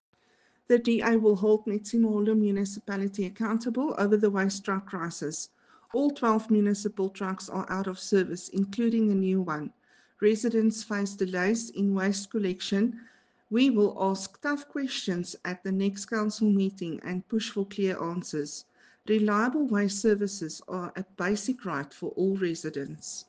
Afrikaans soundbites by Cllr Ruanda Meyer and